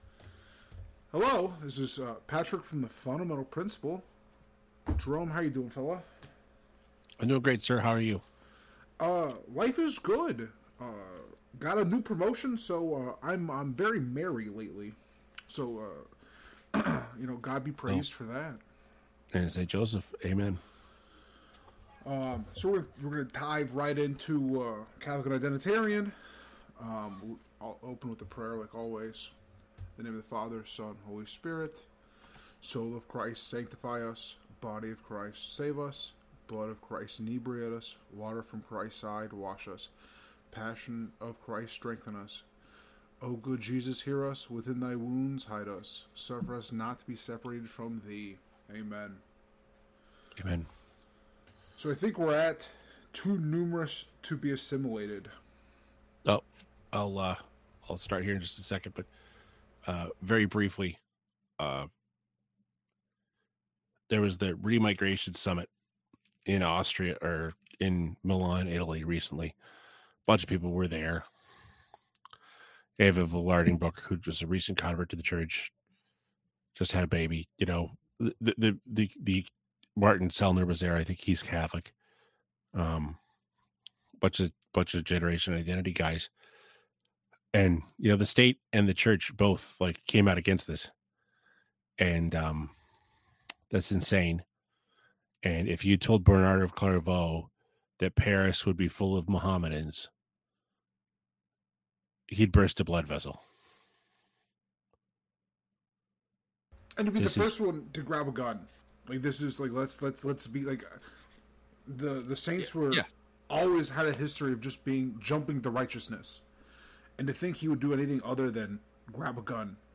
1 BEST OF 520: Jeff Teague’s crazy story of Tracy McGrady punching him, Adam Silver on Luka Doncic trade 1:02:05 Play Pause 13m ago 1:02:05 Play Pause Play later Play later Lists Like Liked 1:02:05 In this week's BEST OF CLUB 520, we look back at Jeff Teague and the guys top moments of the week. In an exclusive interview with Adam Silver, the NBA Commissioner talks to the guys about Nico Harrison and the Mavericks trading superstar Luka Doncic to the Lakers.